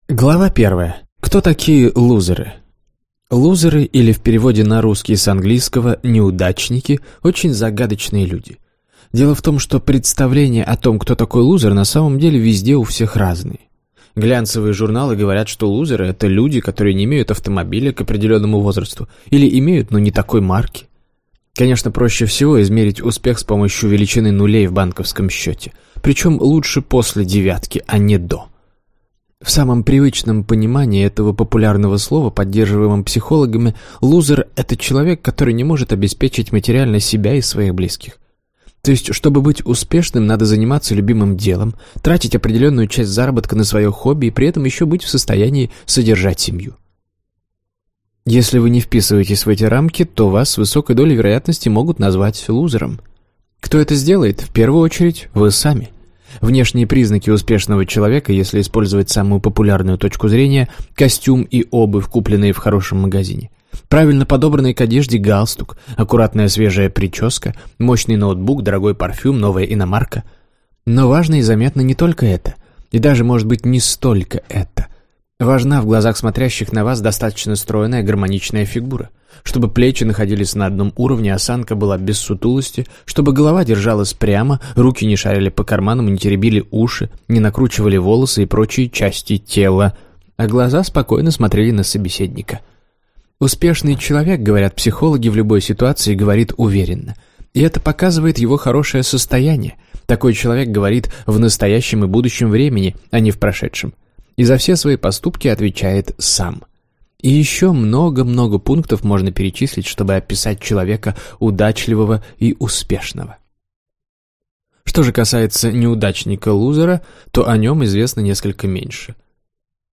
Аудиокнига Привычки неудачников. Ты не станешь успешным, если…